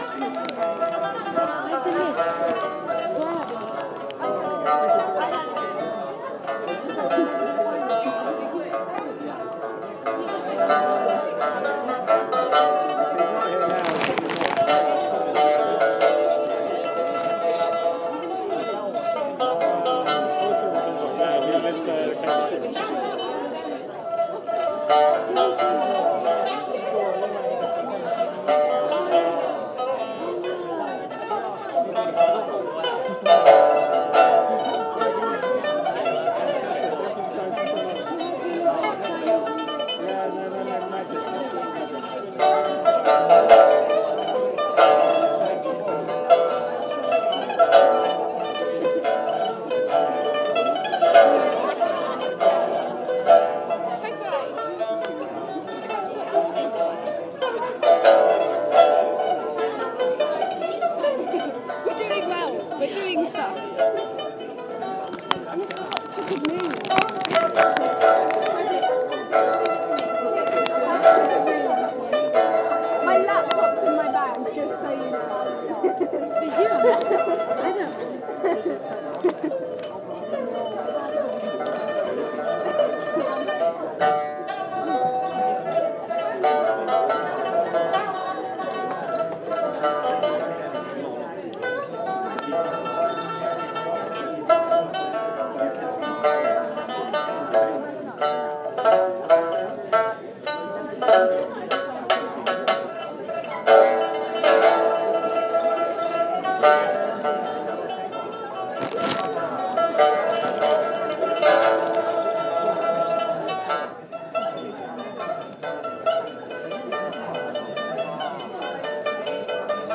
Demo of Chinese 4 stringed lute (pipa) at soas